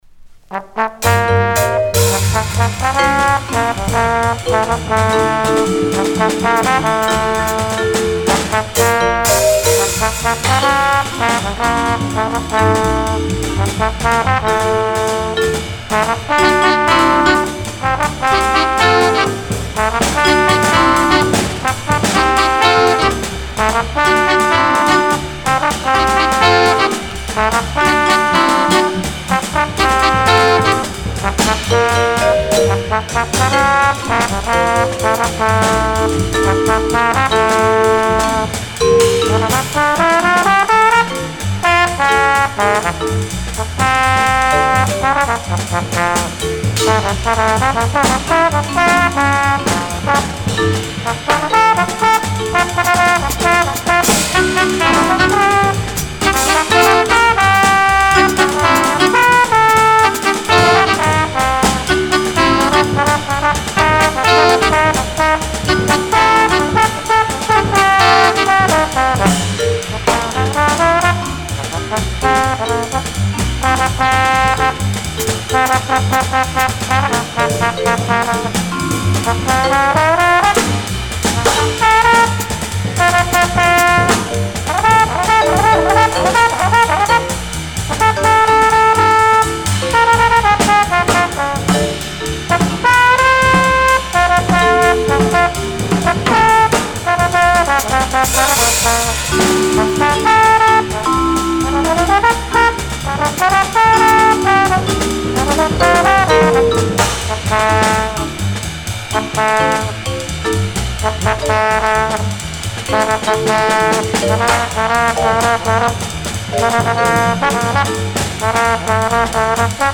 Original mono pressing